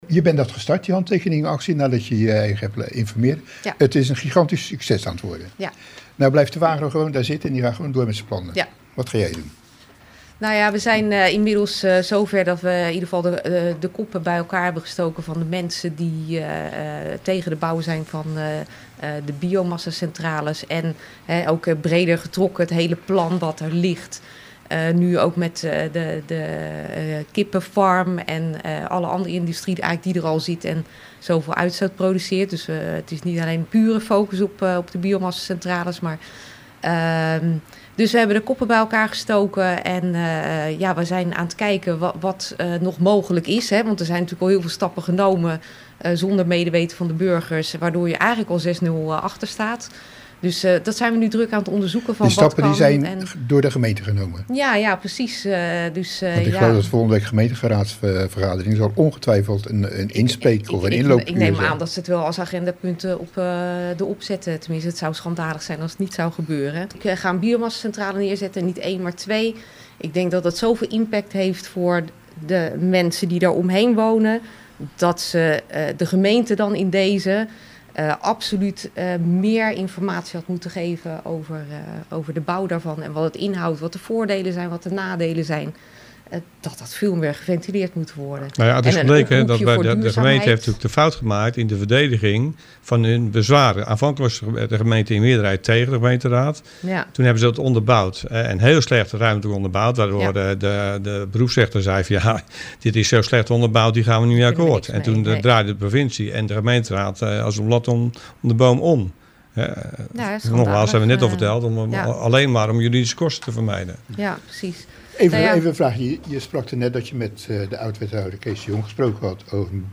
Interview bij RTW over biomassacentrales